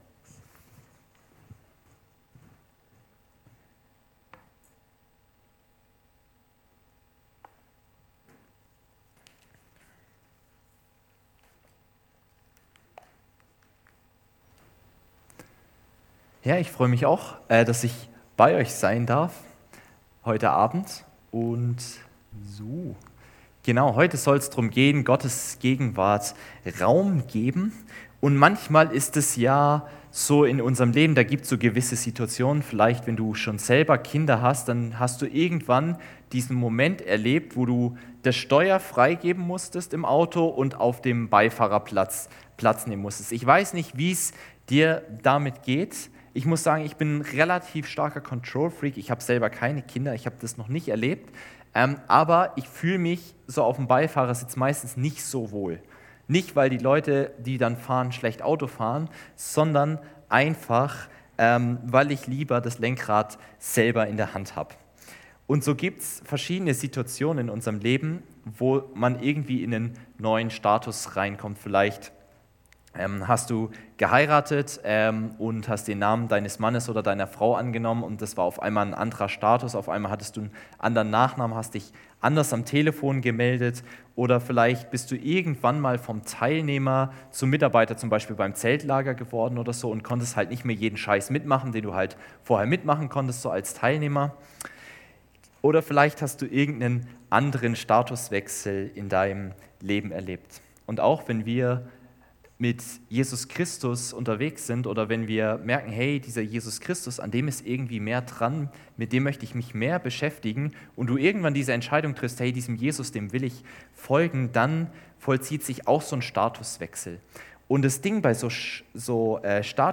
Predigt am 26.05.2024